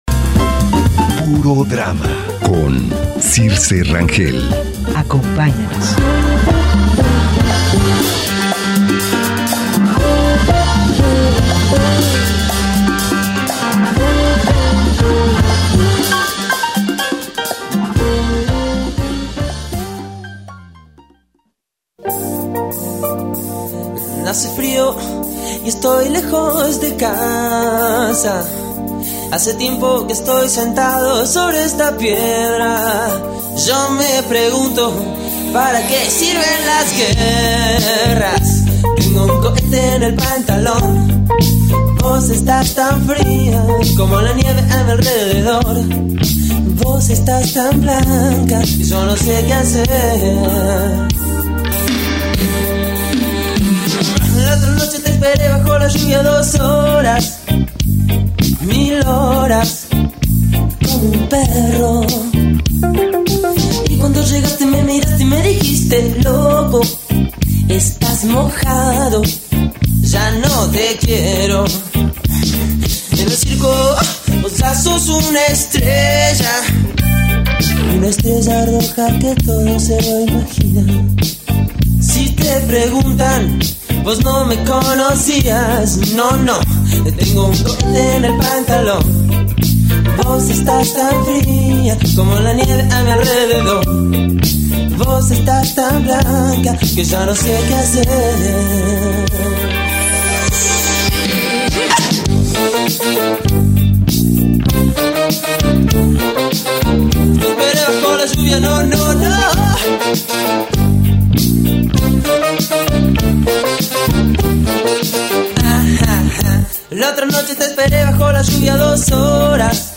En esta emisión nos visitan de tres puestas en escena y un taller de danza butoh. Escucha las conversaciones que tuvimos.